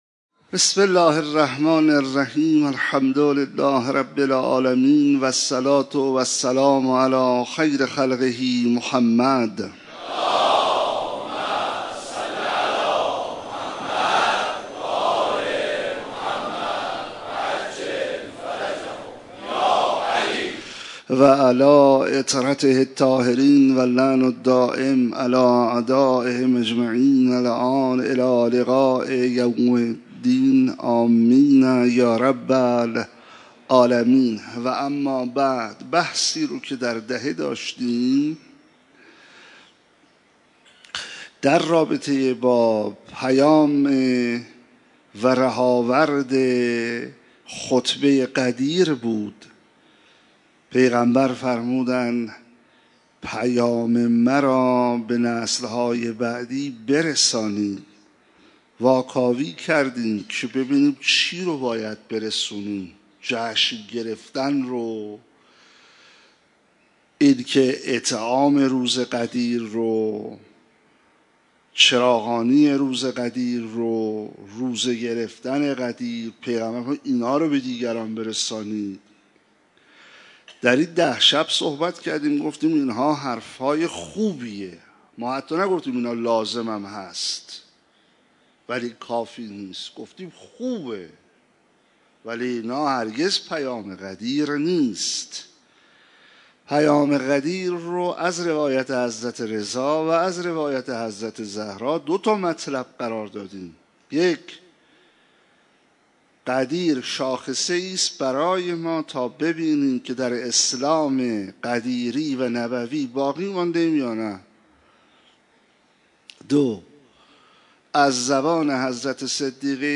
مراسم عزاداری شب دهم محرم مسجد امیر (ع)
صوت کامل این سخنرانی را اینجا بشنوید: